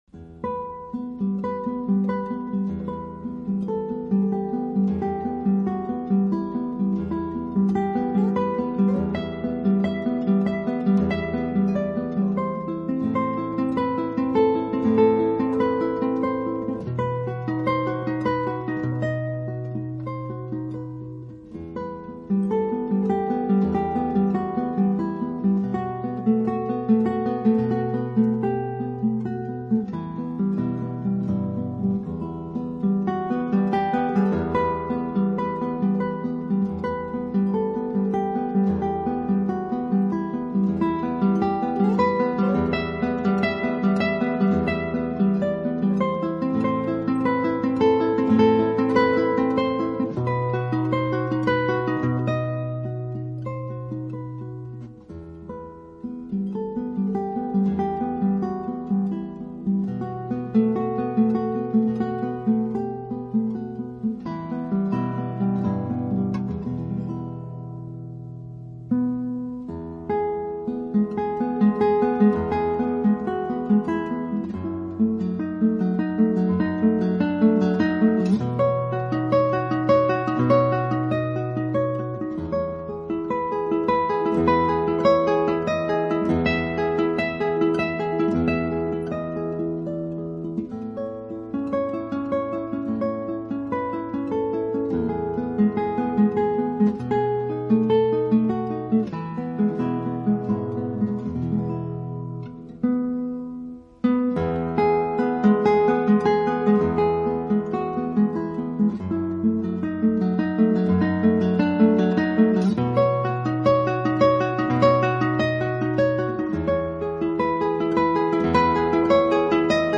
音乐类型: 吉它独奏
完美的演绎，弹奏出成熟而富阳光气息的吉他音乐，浓郁、温柔的音
及深厚的古典音乐造诣，但专辑内处处弥漫着一片轻盈、悠然气息，